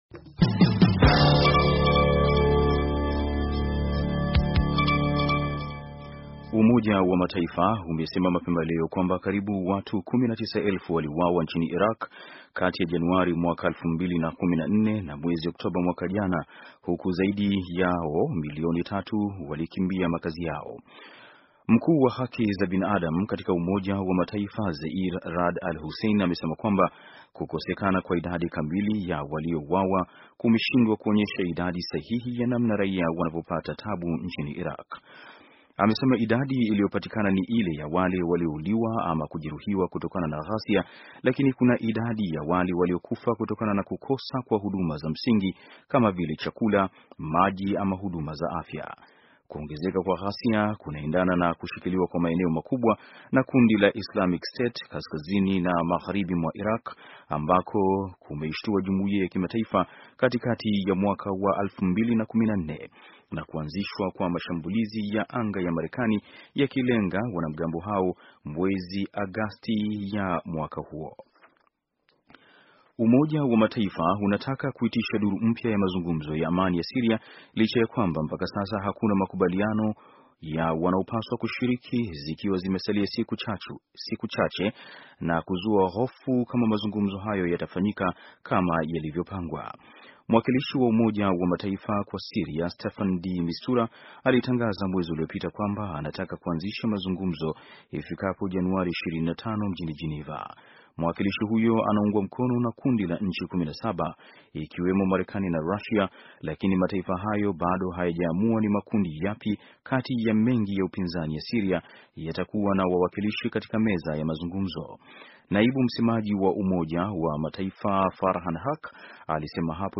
Taarifa ya habari - 4:54